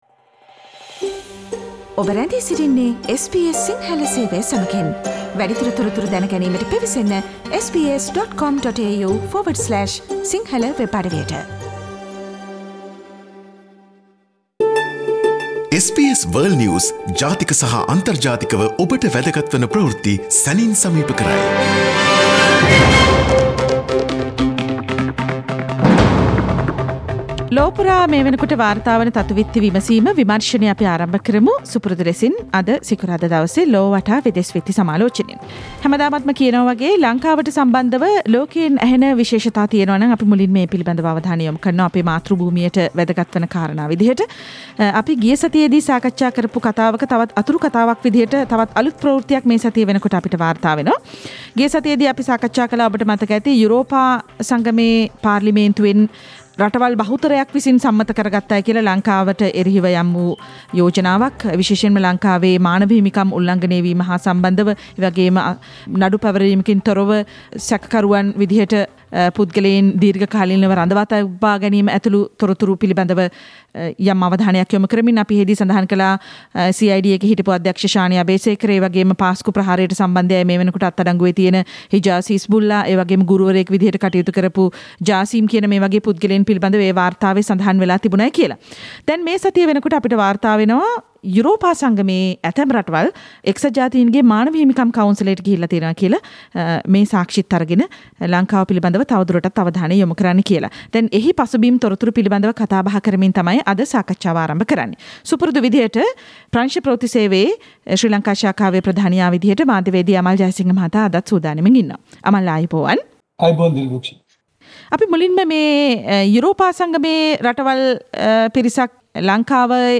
Listen to the latest news from around the world this week from our weekly "Around the World" foreign news review